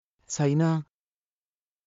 当記事で使用された音声（ネパール語および日本語）は全てGoogle翻訳　および　Microsoft TranslatorNative Speech Generation、©音読さんから引用しております。